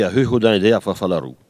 Elle crie pour faire faire la roue aux dindons
Catégorie Locution